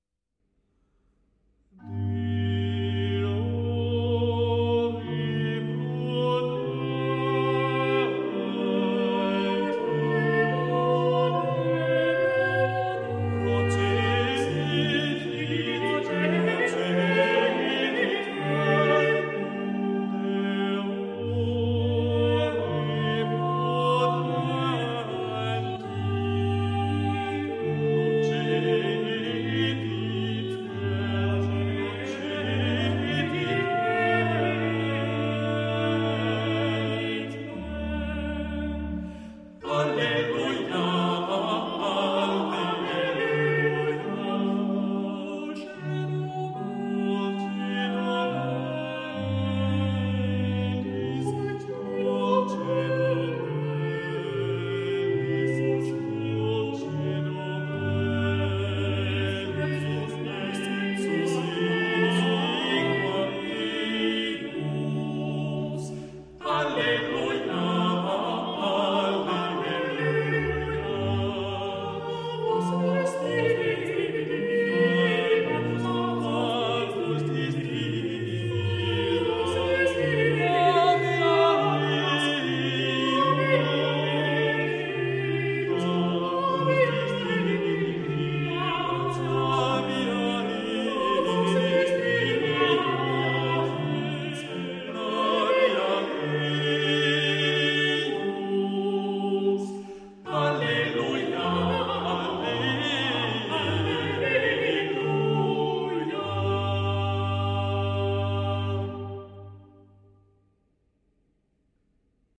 Polyphonic of 3 voices, where several voices sing words.
motet a trois voix et continuo